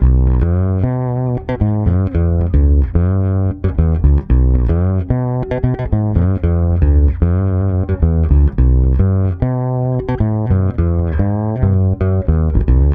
-AL AFRO C#.wav